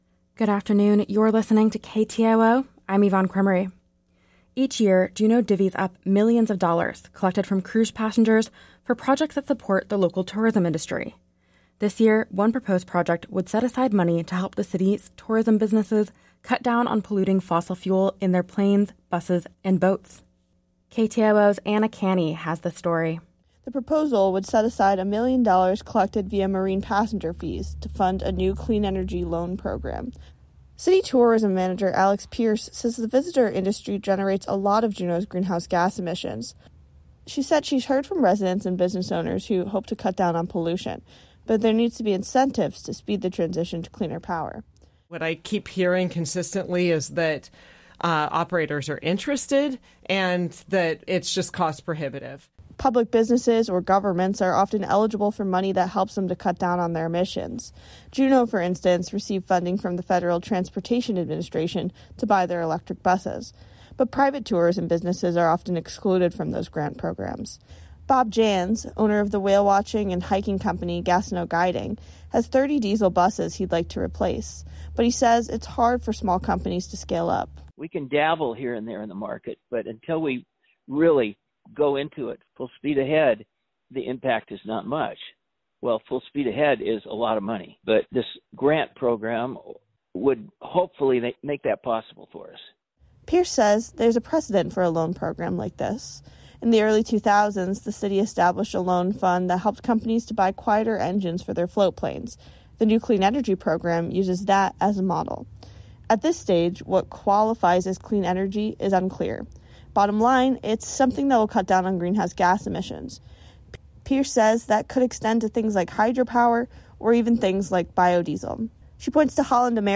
Newscast – Wednesday, April 3, 2024